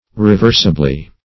Search Result for " reversibly" : Wordnet 3.0 ADVERB (1) 1. in a reversible manner ; - Example: "reversibly convertible" The Collaborative International Dictionary of English v.0.48: Reversibly \Re*vers"i*bly\, adv.